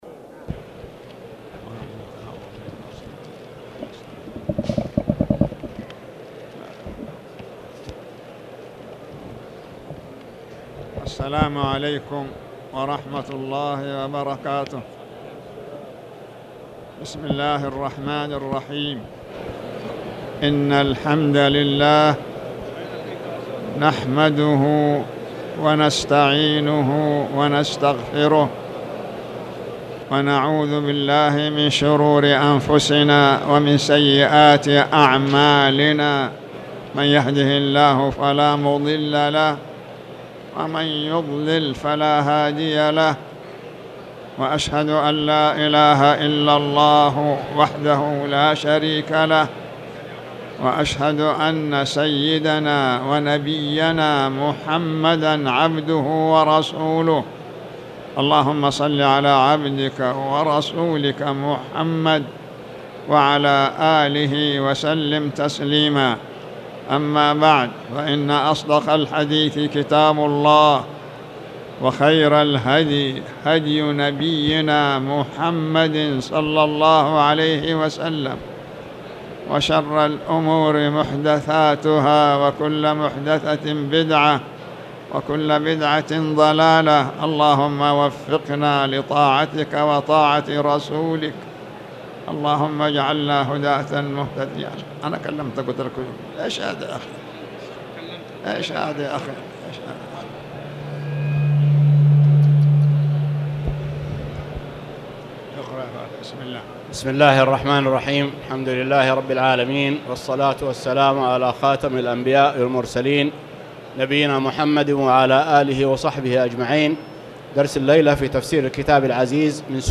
تاريخ النشر ٣٠ جمادى الأولى ١٤٣٨ هـ المكان: المسجد الحرام الشيخ